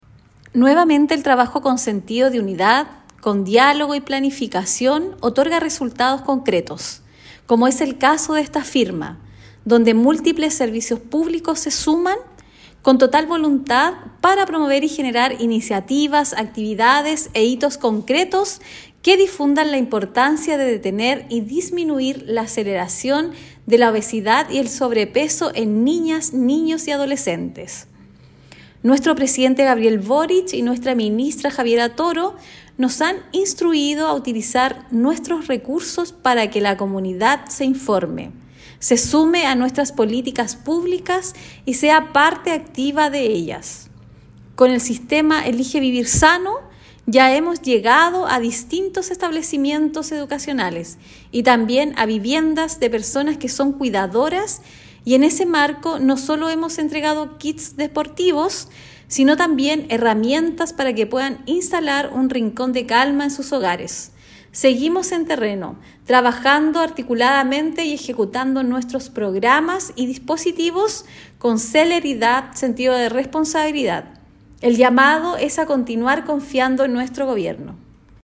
La Seremi de Desarrollo Social, Verónica Rivera, enfatizó el enfoque colaborativo: